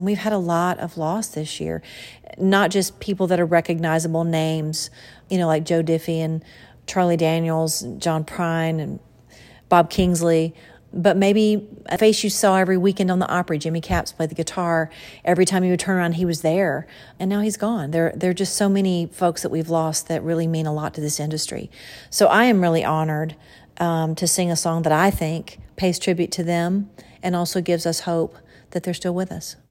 Here’s what Trisha had to say about the song and performance:
Trisha-Yearwood-4-Trisha-Yearwood-talks-about-the-ACM-Awards-tribute-performance.wav